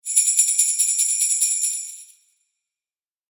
ジングルベルシャカシャカ.mp3